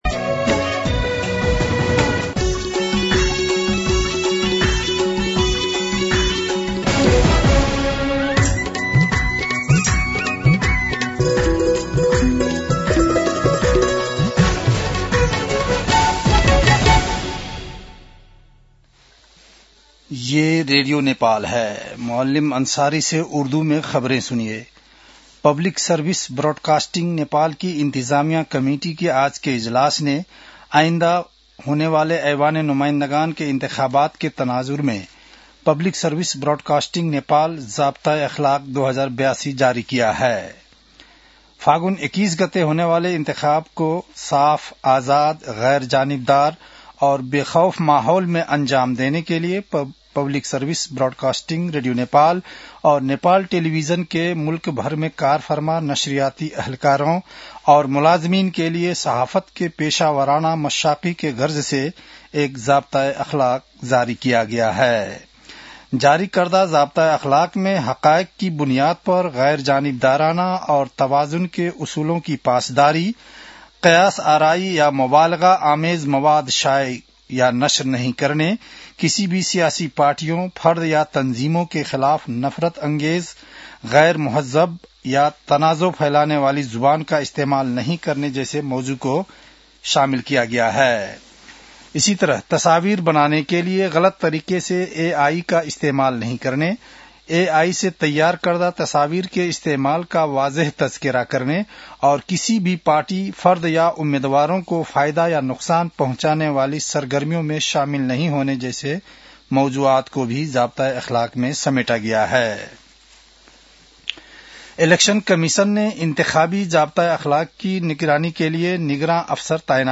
An online outlet of Nepal's national radio broadcaster
उर्दु भाषामा समाचार : १४ माघ , २०८२